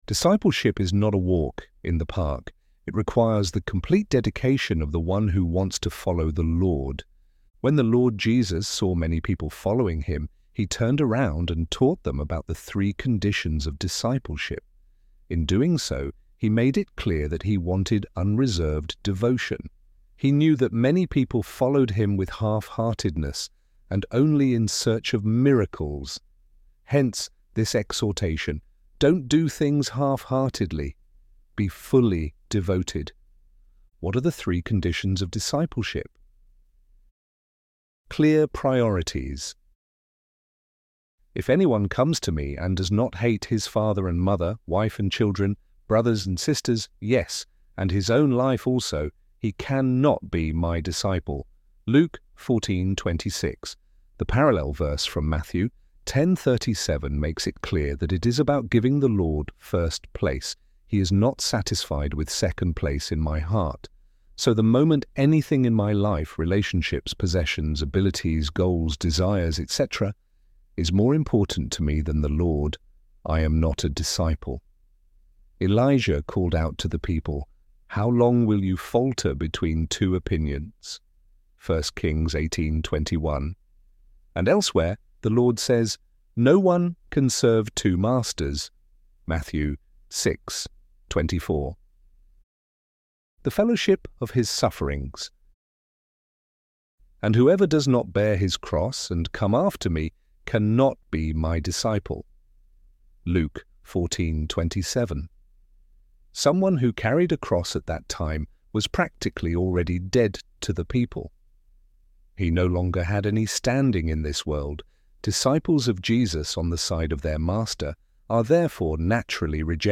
ElevenLabs_Conditions_of_Discipleship.mp3